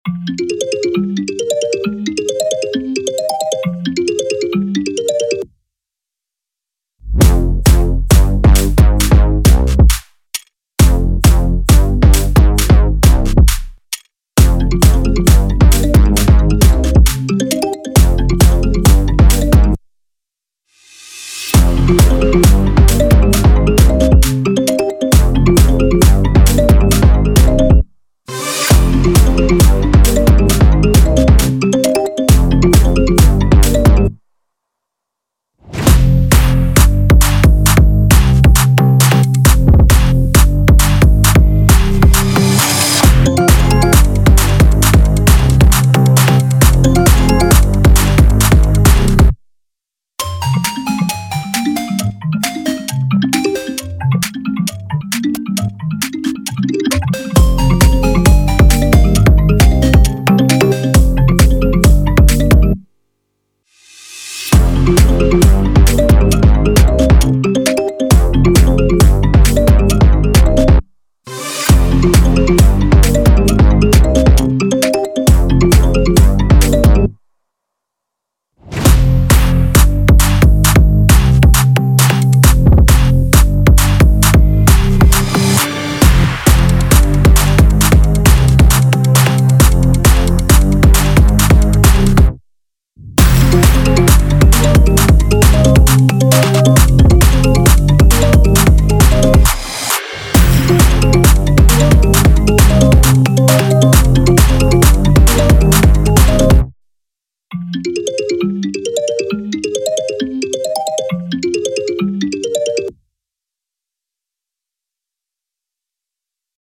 official instrumental
Pop Instrumentals